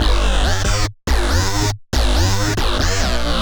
FR_Synco_140-G.wav